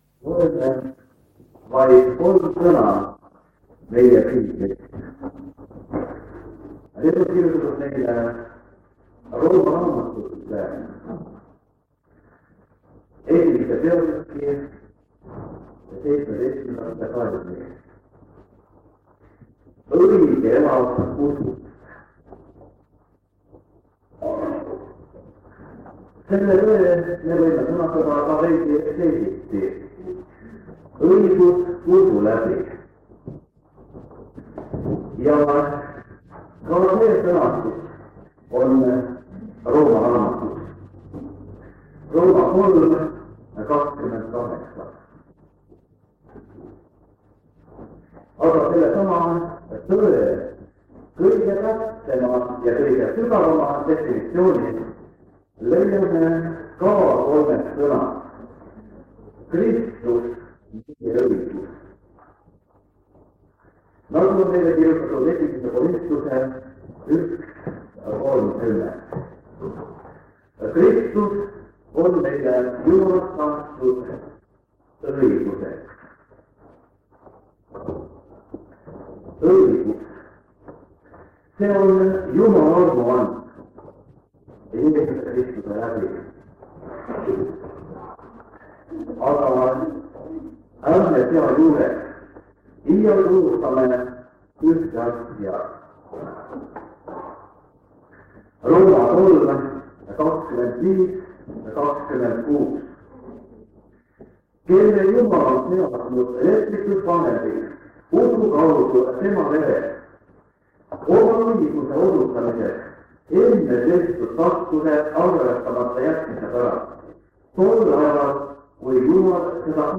Jutluste miniseeria vanadelt lintmaki lintidelt 1974 aasta sügisel. Teemaks on ÕIGUS USU LÄBI OSA 4 (ilma lõputa)